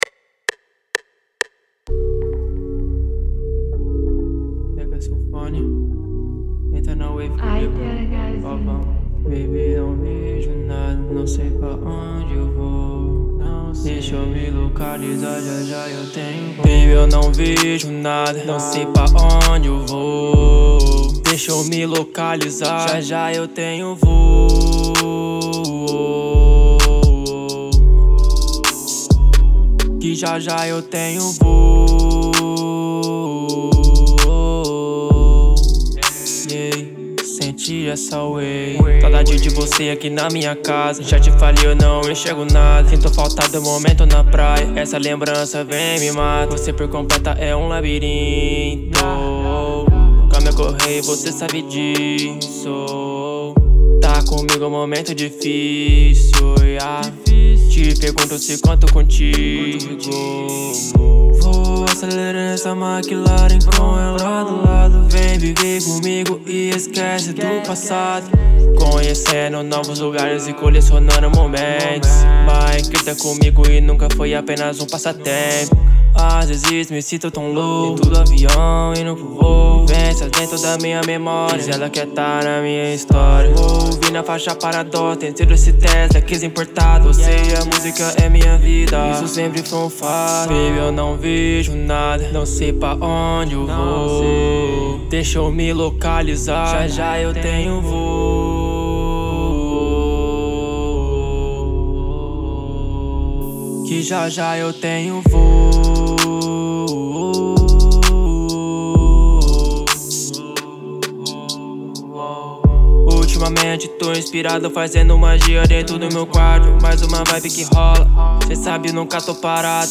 EstiloHip Hop / Rap